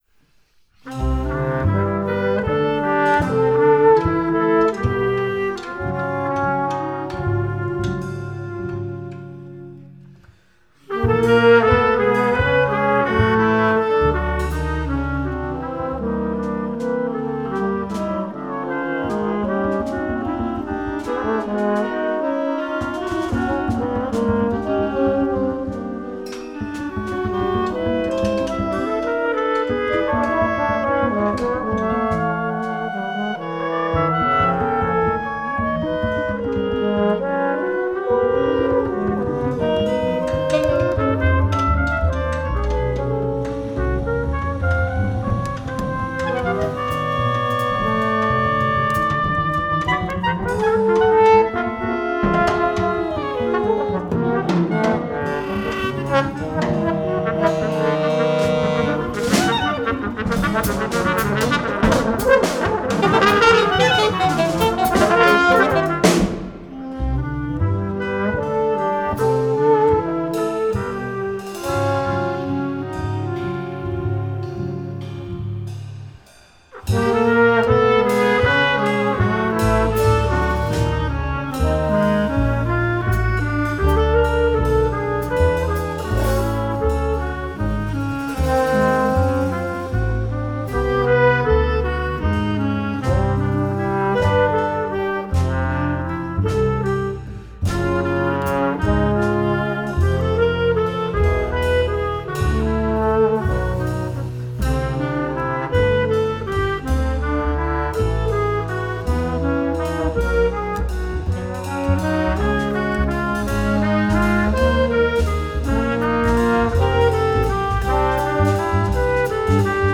at E-Sound Studios, Weesp, NL